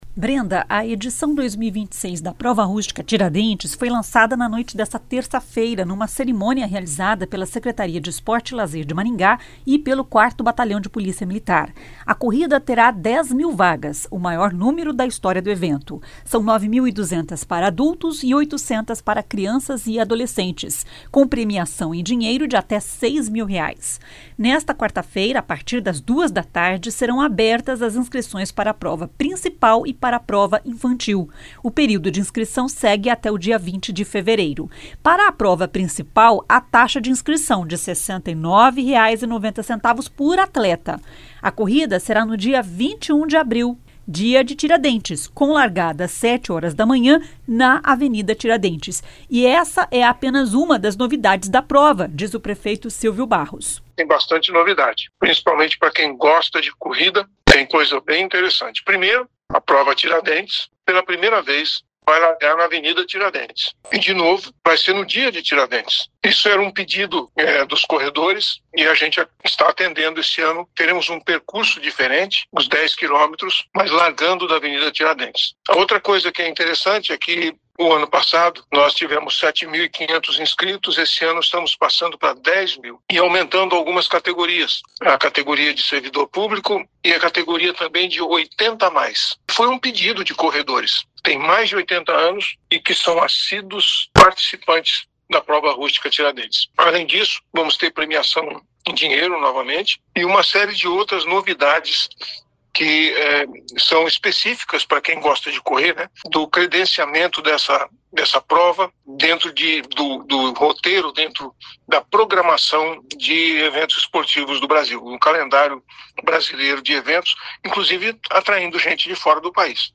E essa é apenas uma das novidades da prova, diz o prefeito Sílvio Barros.